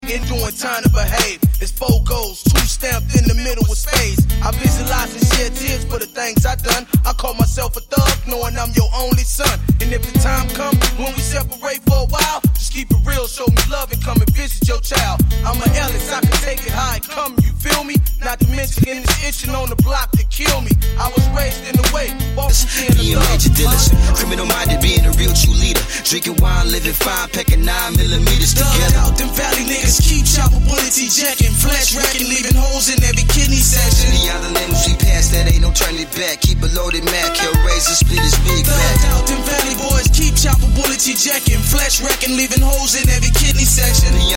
Bay Area gangsta rap